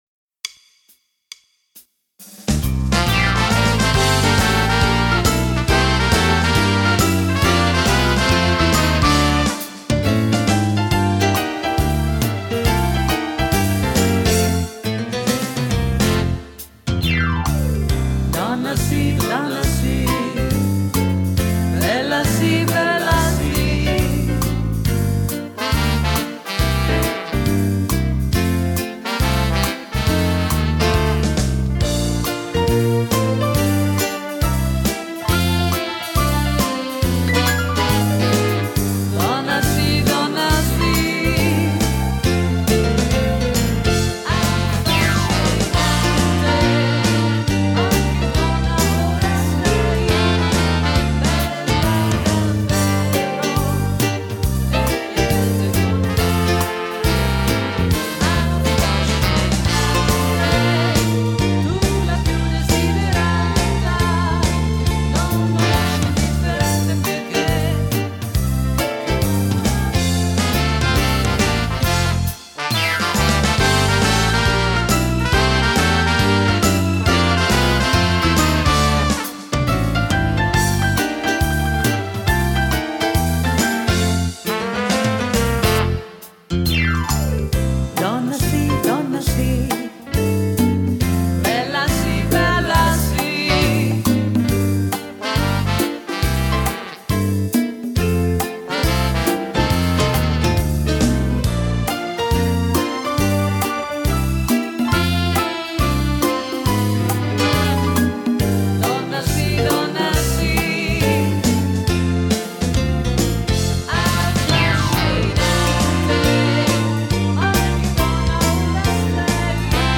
Fox trot
Uomo